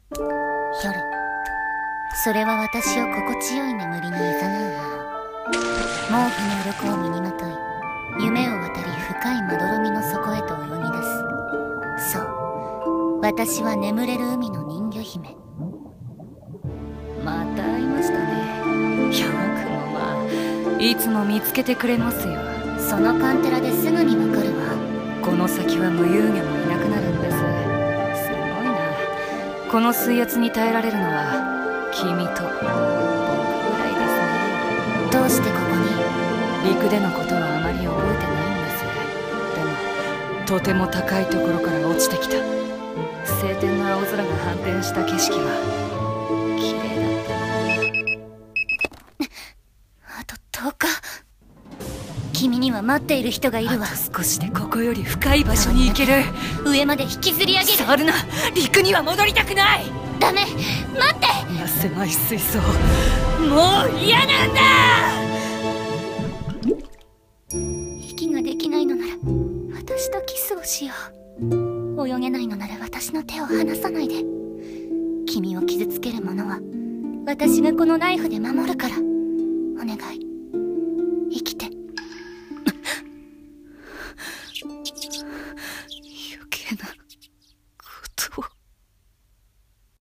CM風声劇「睡深8400mより」